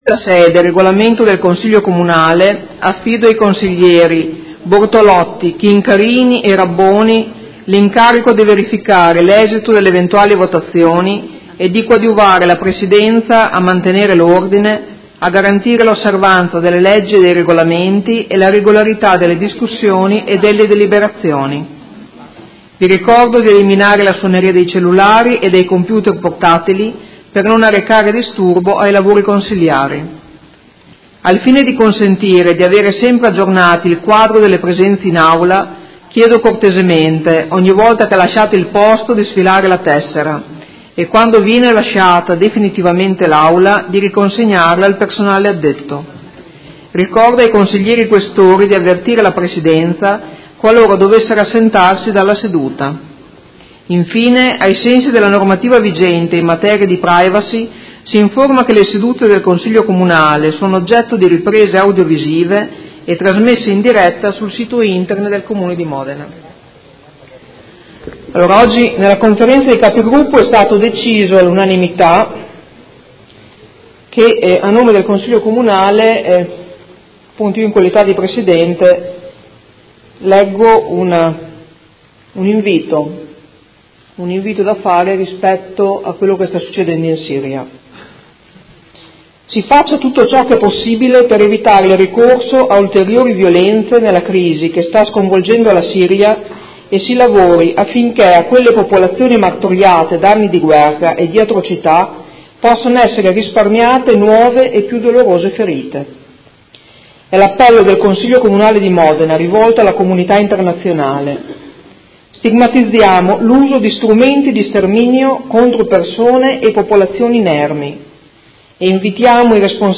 Seduta del 12/04/2018 Comunicazioni d' apertura. Comunicazione: Appello su conflitto in Siria.
Presidentessa